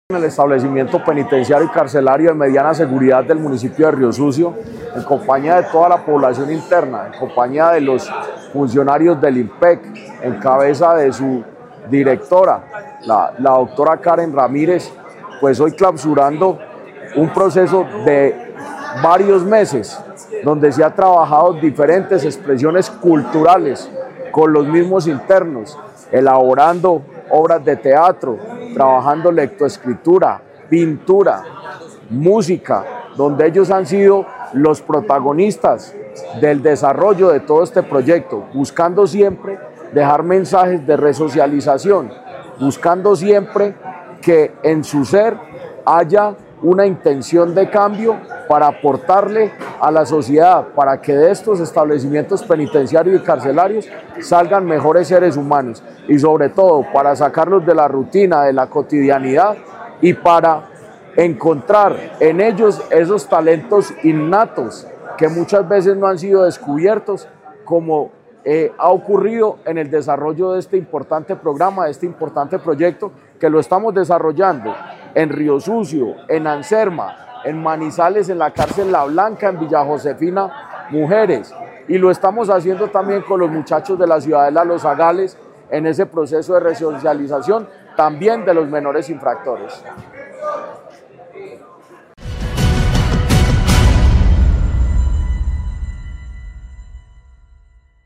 Secretario de Gobierno de Caldas, Jorge Andrés Gómez Escudero.